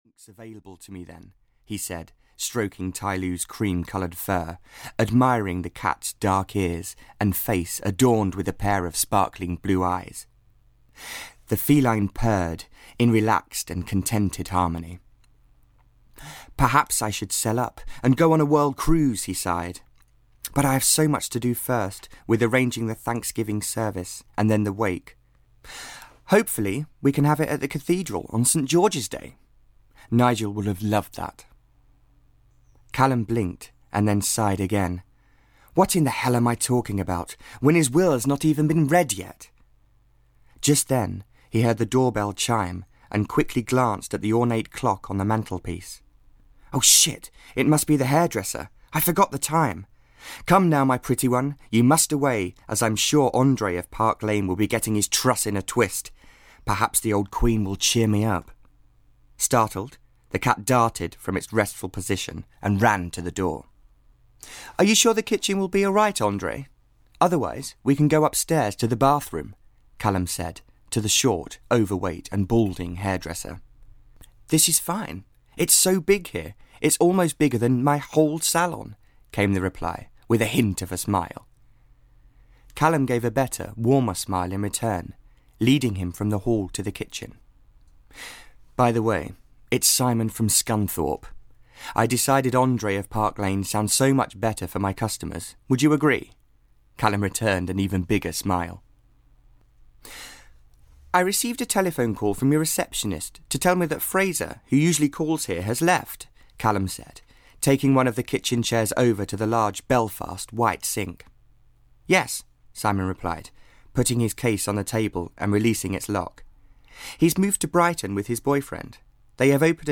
Tail Spin (EN) audiokniha
Ukázka z knihy